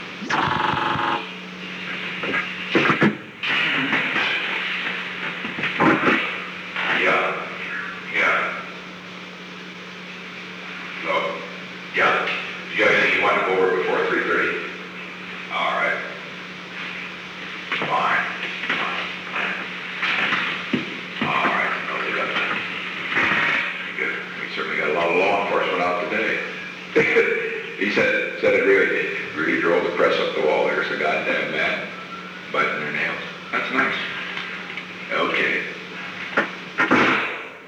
Secret White House Tapes
Conversation No. 509-15
Location: Oval Office
John D. Ehrlichman talked with the President